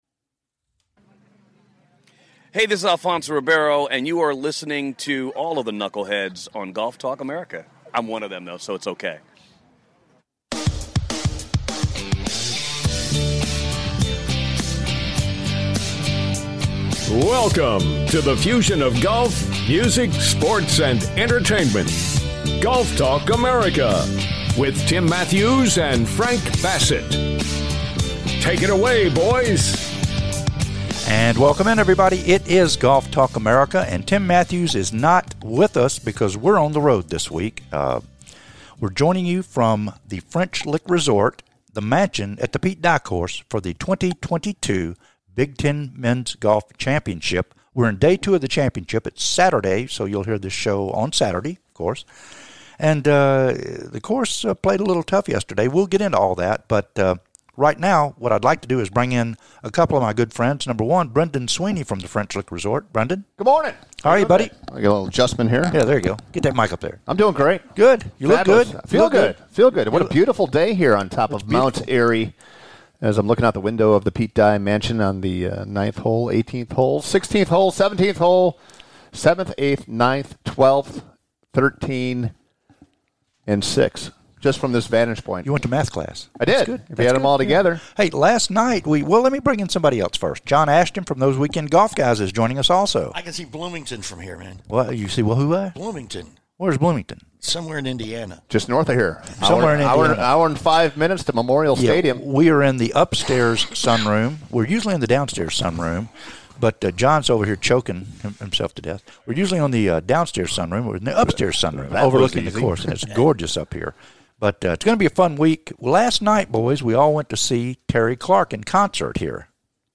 "LIVE"FROM THE 2022 BIG 10 MENS GOLF CHAMPIONSHIPS AT FRENCH LICK
"Confusion & Mayhem" from the French Lick Resort's Pete Dye Course during the 2022 Big 10 Mens Golf Championships.